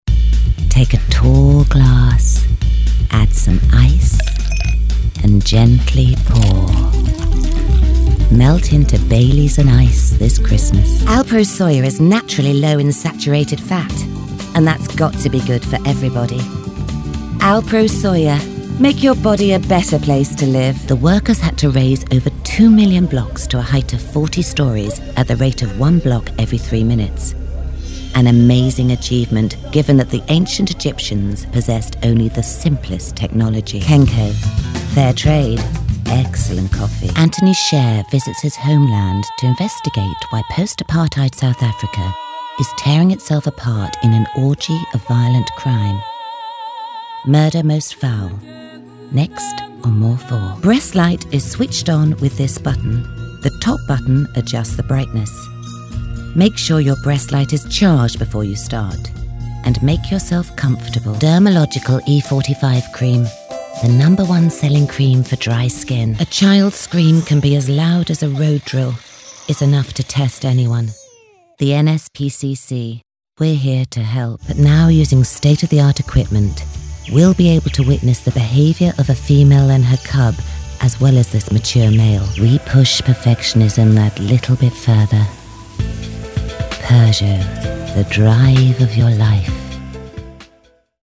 Voiceover showreel 2018